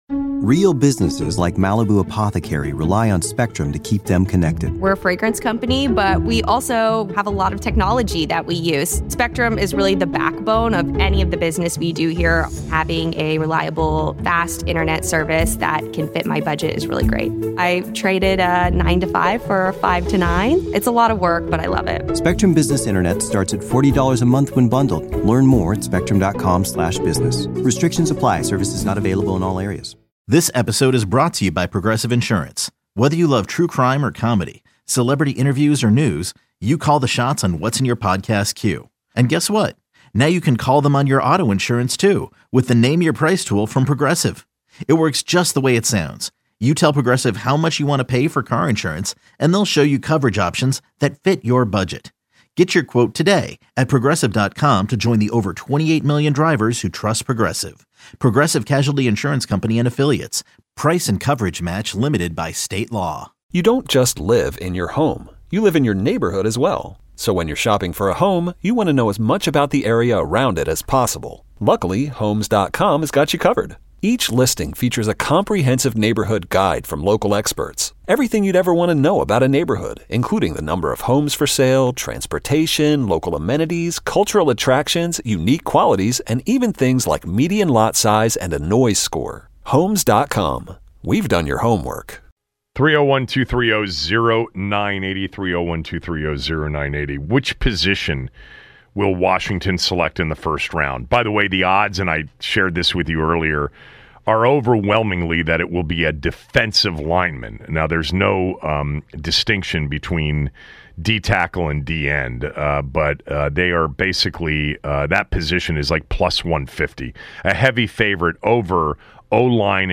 Callers give their ideal position they want the Commanders to draft in the first round of the NFL Draft.